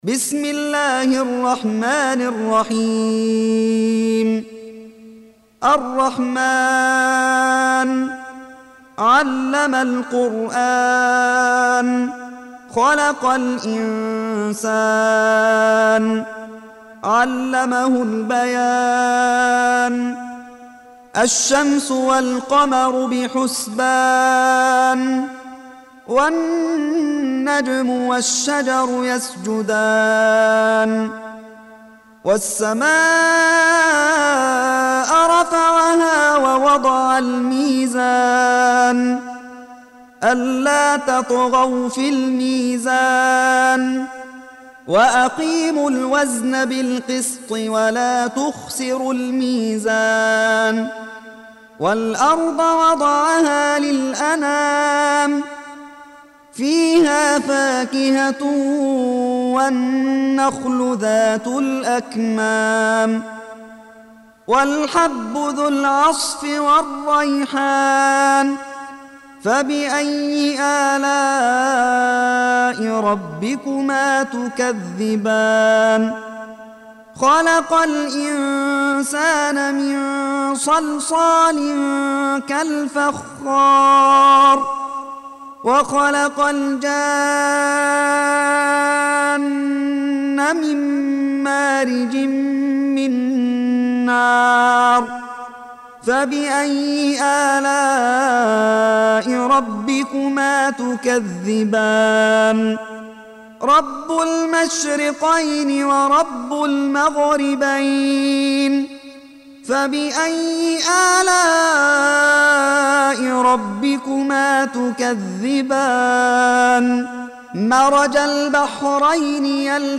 55. Surah Ar-Rahm�n سورة الرحمن Audio Quran Tarteel Recitation
Surah Sequence تتابع السورة Download Surah حمّل السورة Reciting Murattalah Audio for 55.